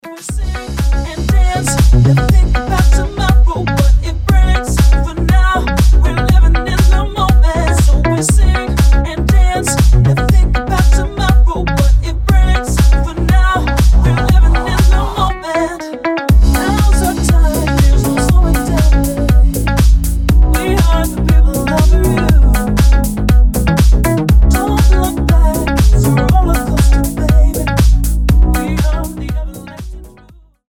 • Качество: 256, Stereo
deep house
dance
club
vocal
Подвижный хаус с женским вокалом